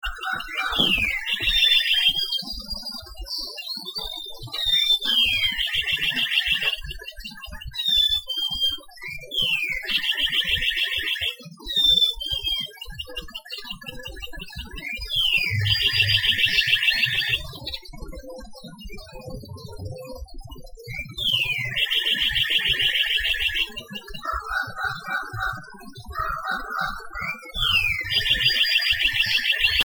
early morning bird songs